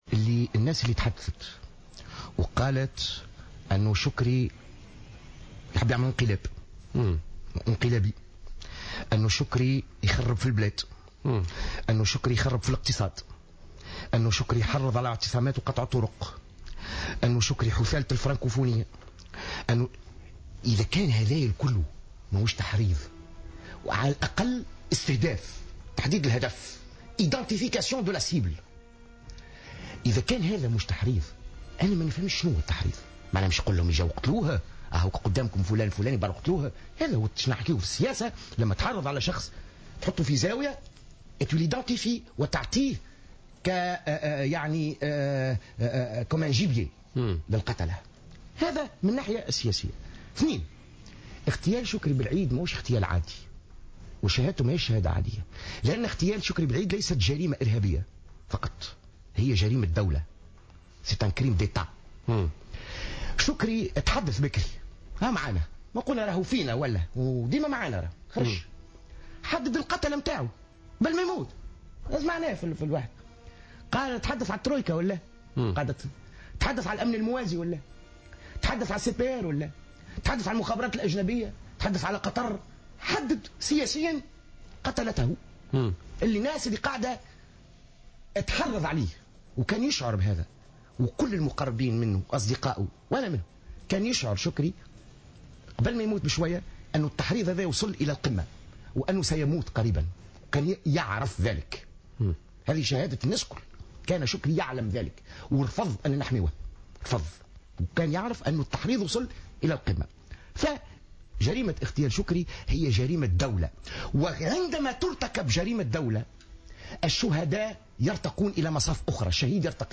Invité sur le plateau de Politca du vendredi 6 février 2015 sur les ondes de Jawhara Fm, Jawhar Ben Mbarek a soutenu que l’assassinat de Chokri Belaïd est un crime d’Etat et a exposé ses arguments.